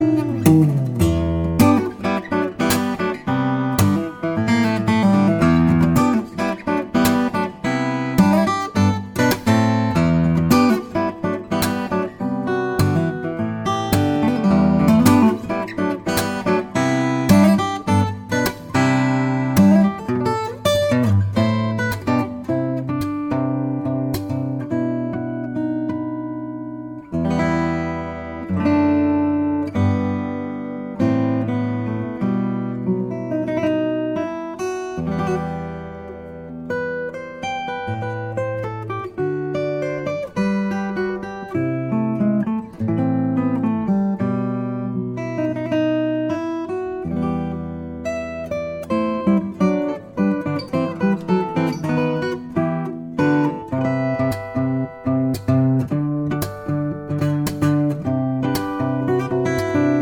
• Sachgebiet: Liedermacher